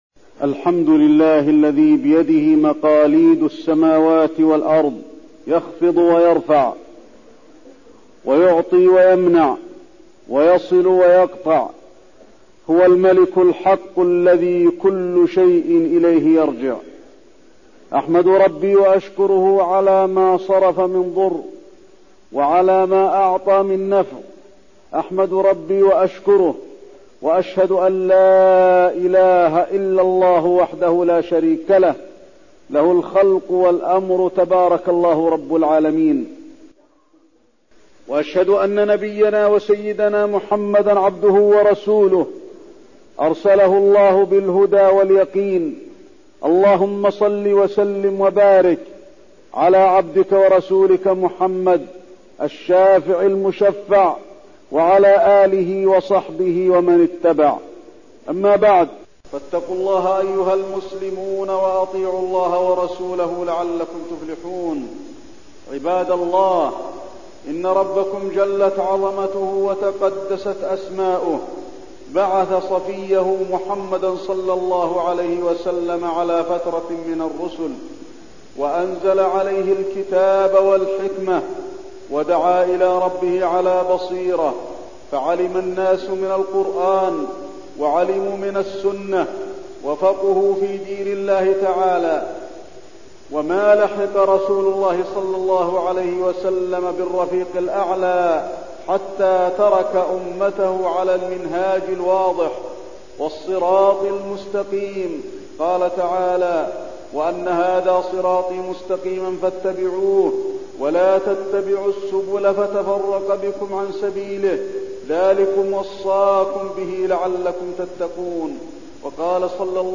تاريخ النشر ٢٥ محرم ١٤٠٨ هـ المكان: المسجد النبوي الشيخ: فضيلة الشيخ د. علي بن عبدالرحمن الحذيفي فضيلة الشيخ د. علي بن عبدالرحمن الحذيفي الدعوة إلى الله على بصيرة